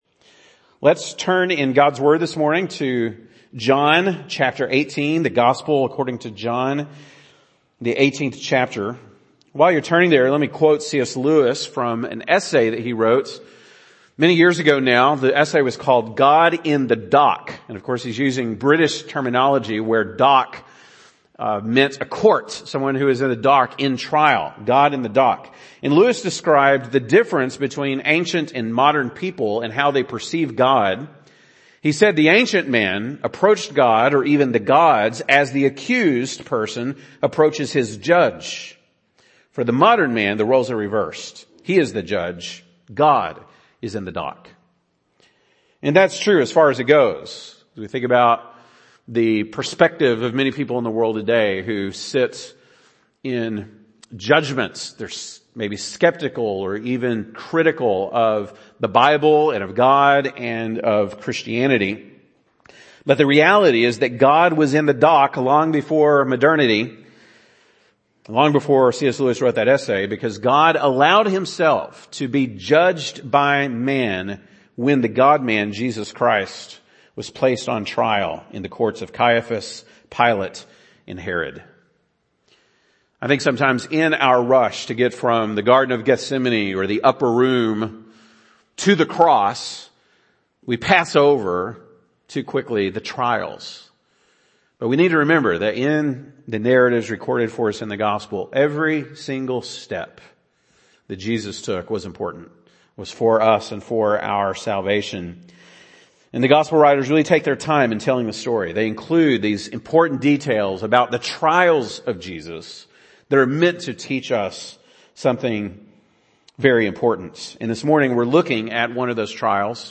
March 20, 2022 (Sunday Morning)